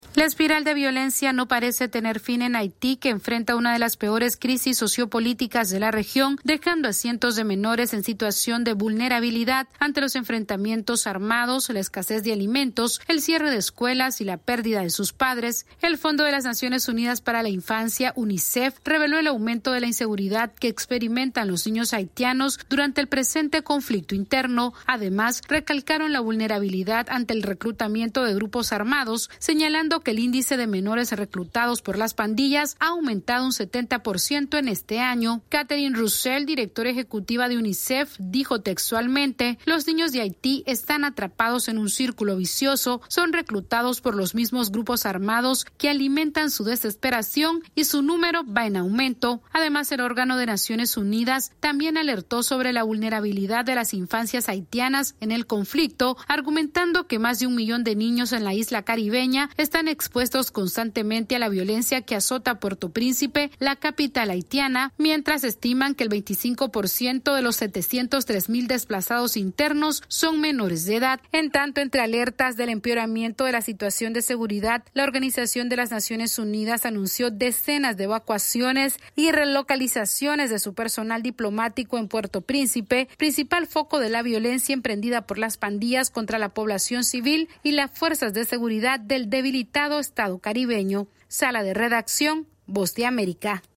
La crisis de seguridad en Haití continúa y los niños y adolescentes son un blanco crítico de los grupos armados que han aumentado el reclutamiento de los menores, según reveló un informe de UNICEF. Esta es una actualización de nuestra Sala de Redacción....